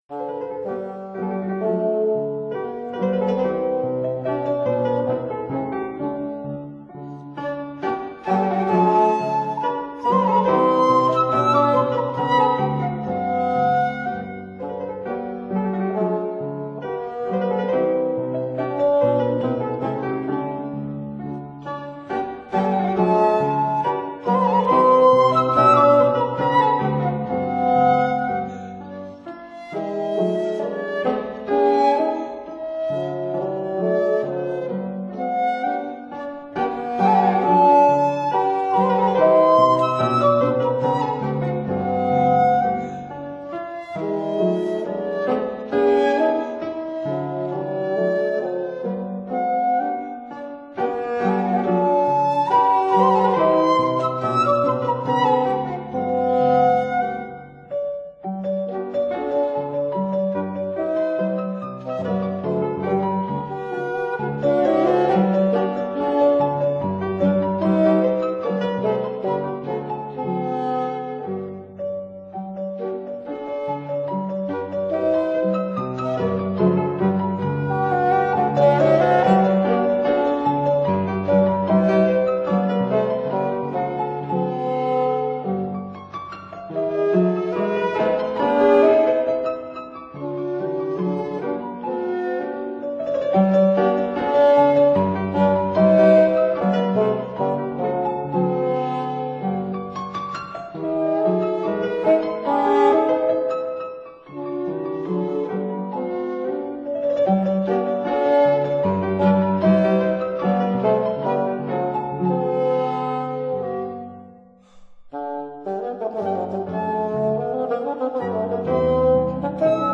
for Bassoon & Piano
(Period Instruments)